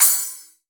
CRASH_T.WAV